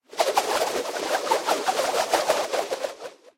На этой странице представлена коллекция звуков канатов, верёвок и тросов: скрип при натяжении, шуршание, удары о поверхность.